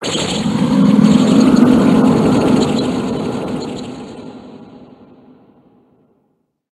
Cri de Dratatin Gigamax dans Pokémon HOME.
Cri_0842_Gigamax_HOME.ogg